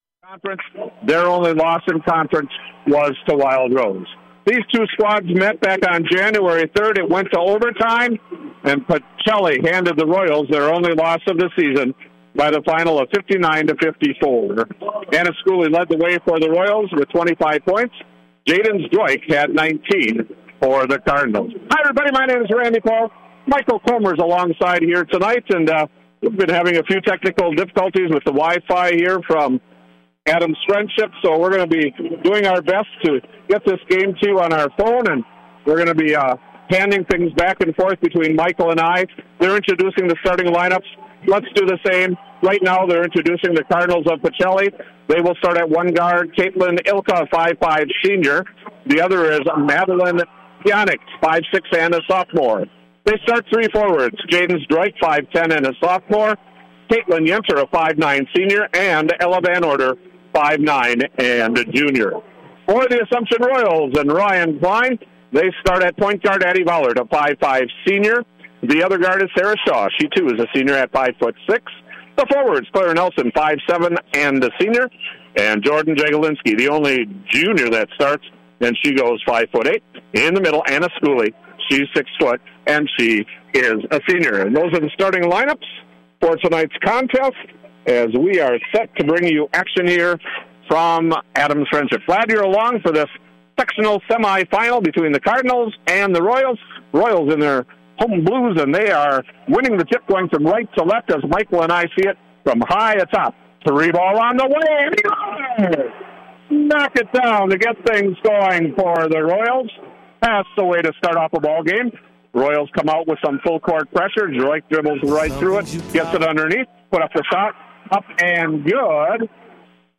Girls Basketball Sectionals - Wisconsin Rapids Assumption v. Pacelli - Civic Media